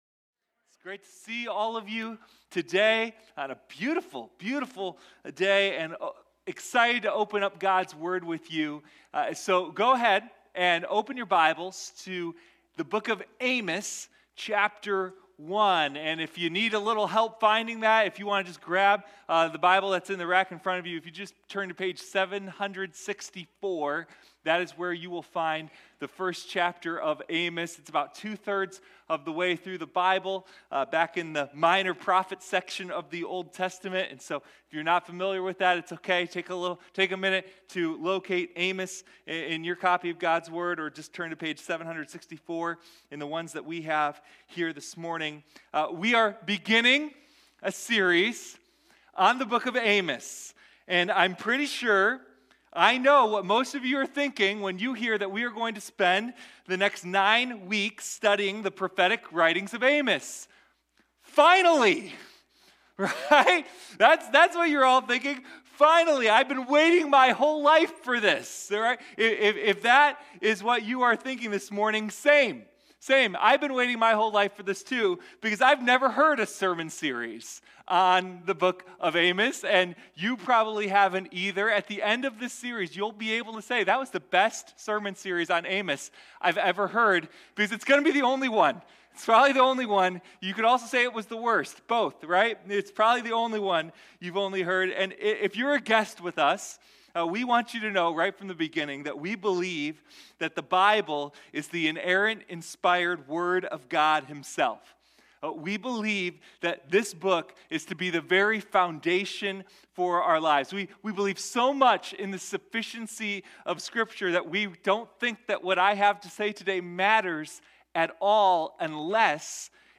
Sunday Morning Amos: The Roar of Justice